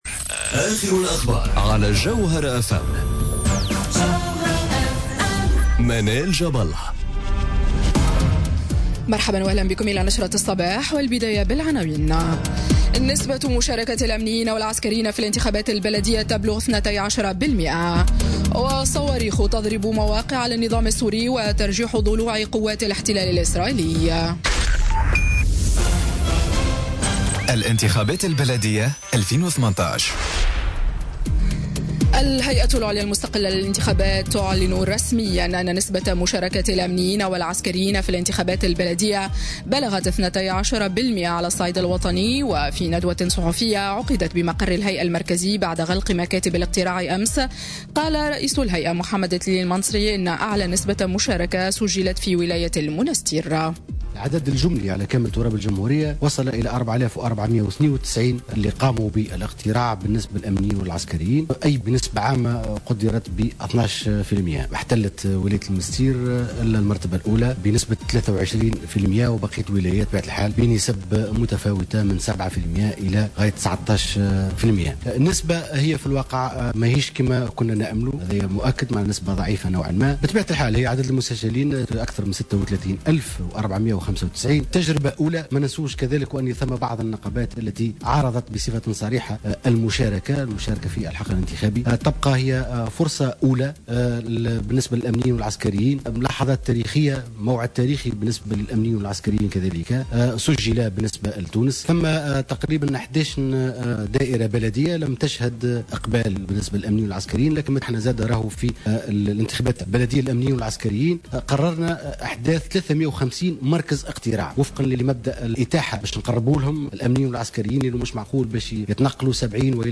نشرة أخبار السابعة صباحا ليوم الإثنين 30 أفريل 2018